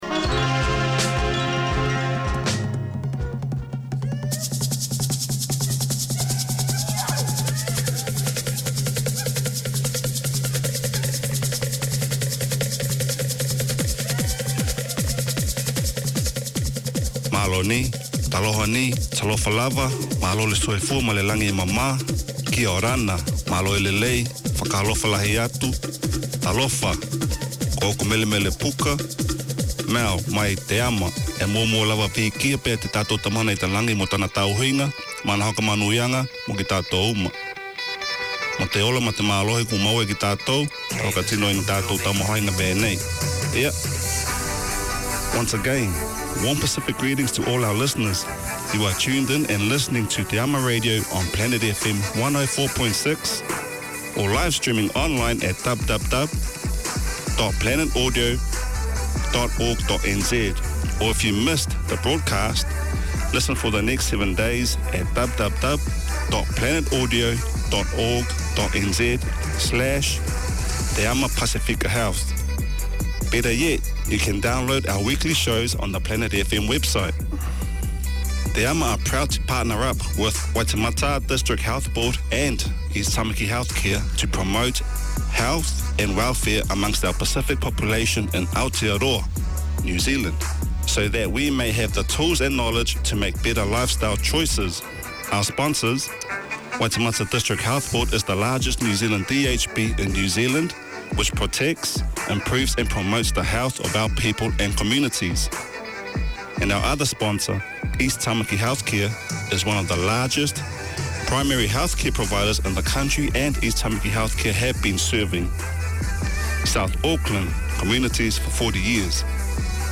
Each week you’ll hear discussion of topics that help you get healthy and stay healthy.